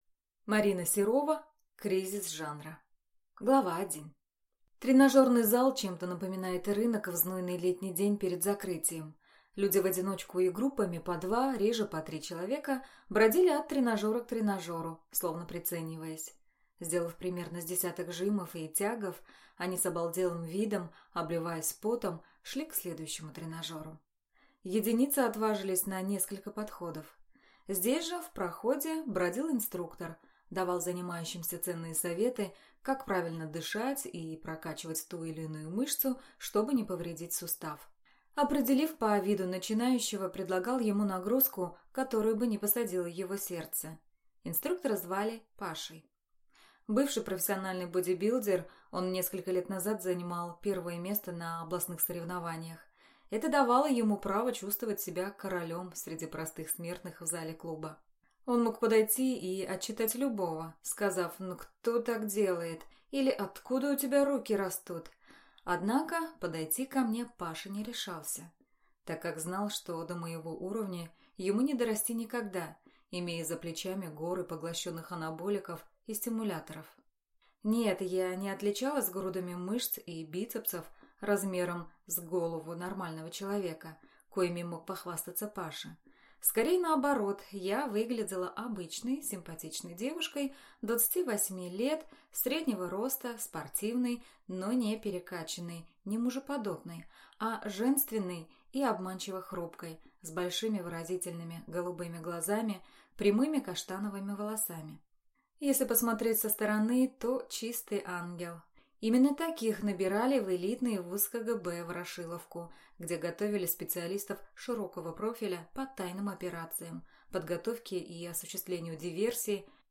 Аудиокнига Кризис жанра | Библиотека аудиокниг